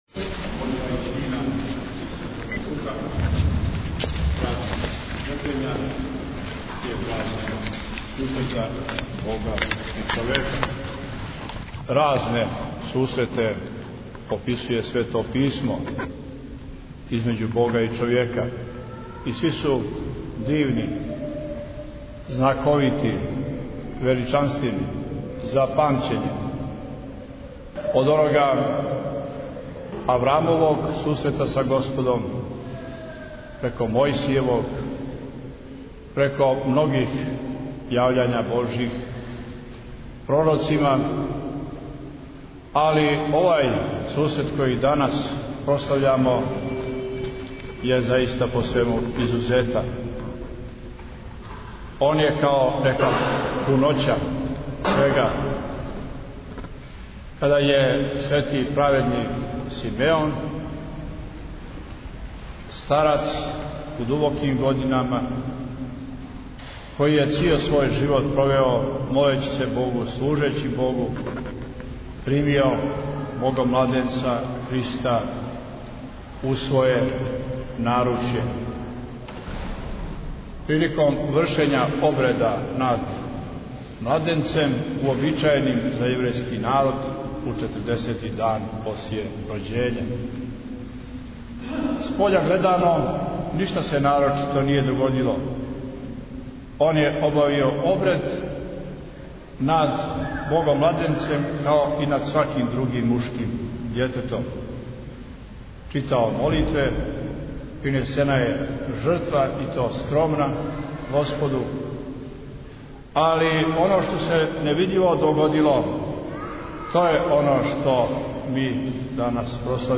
Празник Сретења Господњег молитвено прослављен у никшићком Саборном храму Tagged: Бесједе 9:42 минута (1.39 МБ) На празник Сретења Господњег, у понедјељак 15. фебруара 2016, Његово Преосвештенство Епископ будимљанско-никшићки Г. Јоаникије служио је Свету Архијерејску Литургију у Саборном храму Светог Василија Острошког у Никшићу.